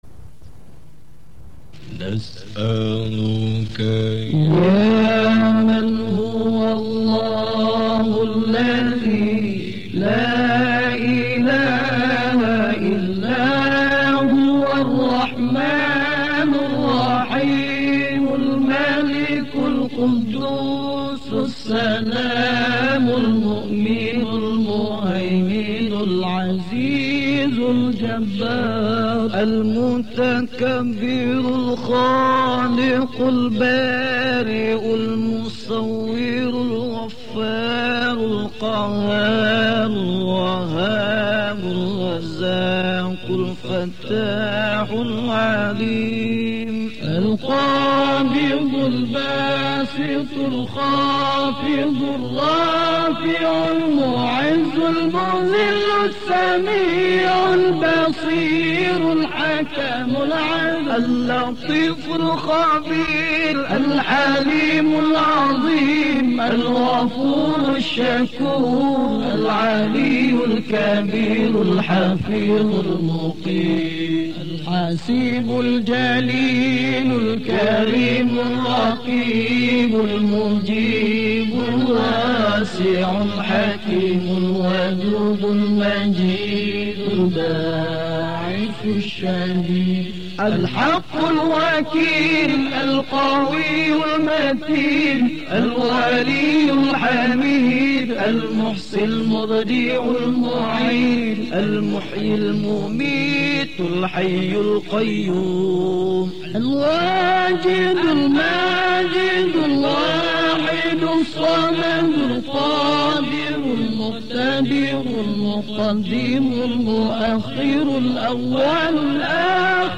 His amazing accent draws in his followers.